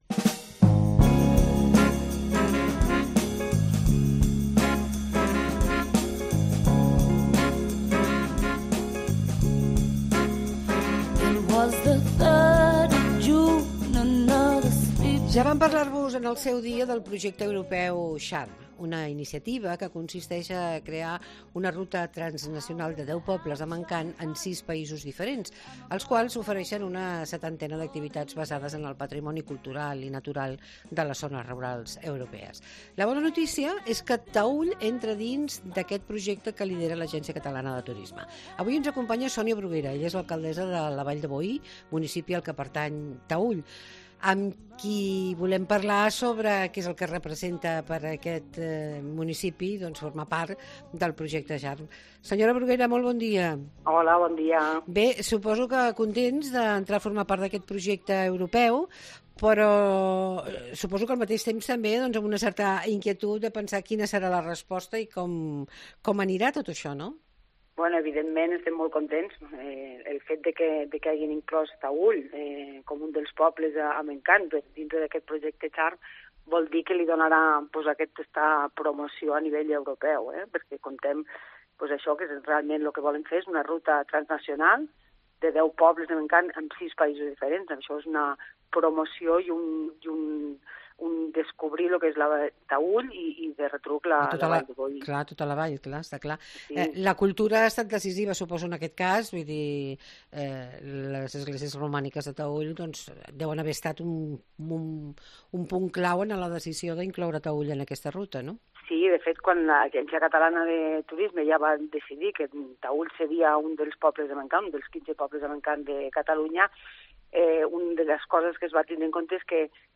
L'alcaldessa de Taüll, Sònia Bruguera, ens parla sobre el que pot representar està dins del projecte Charm per la Vall